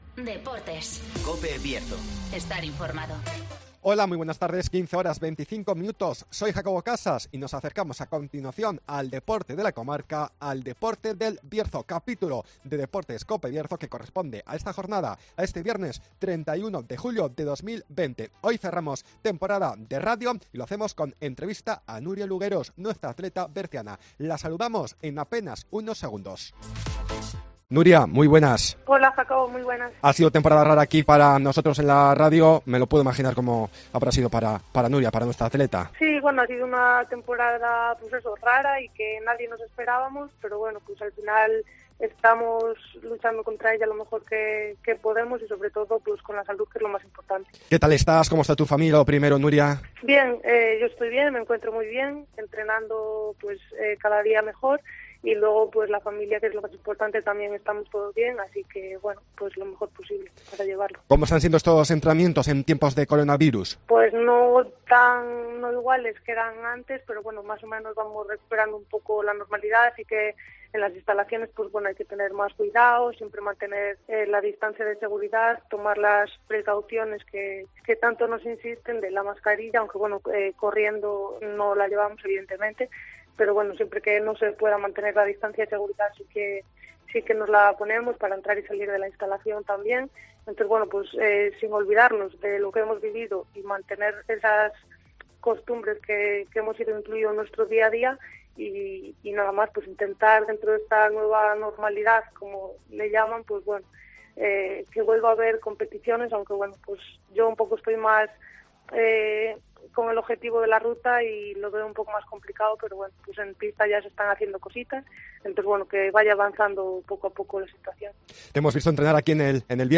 Terminamos temporada -Entrevista